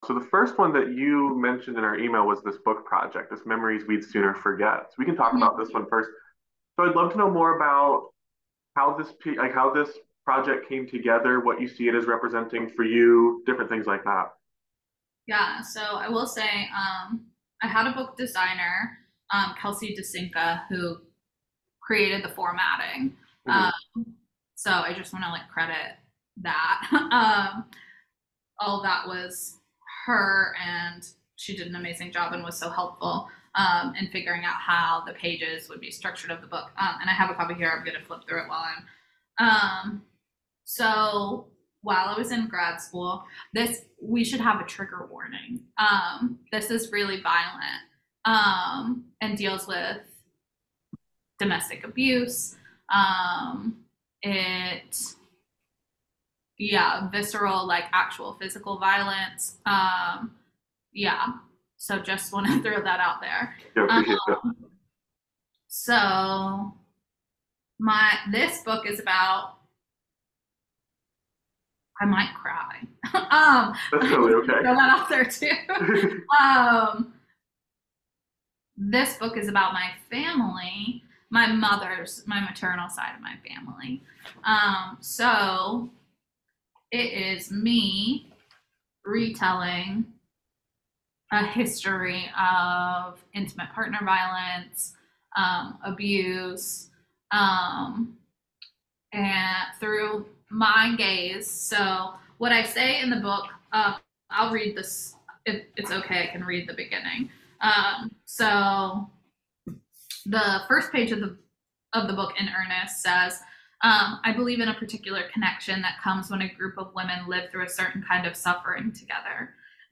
Interview
over Zoom